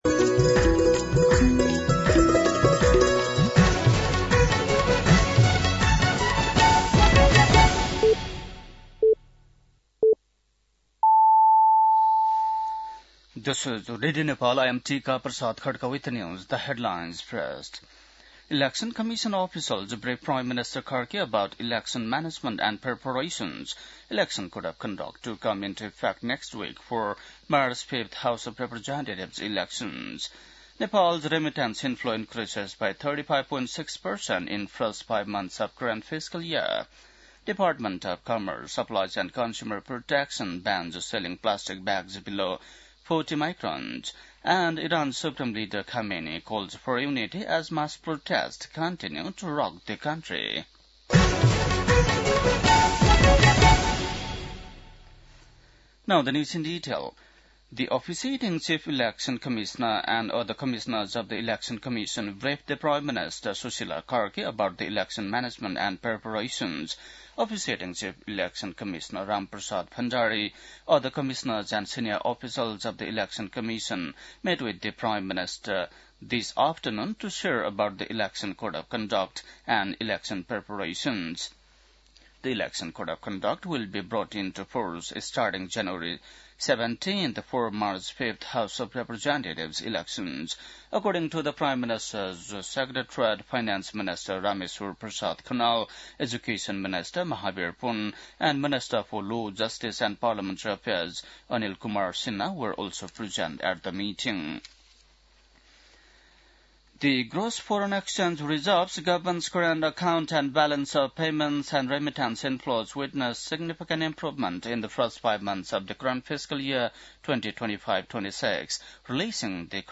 बेलुकी ८ बजेको अङ्ग्रेजी समाचार : २५ पुष , २०८२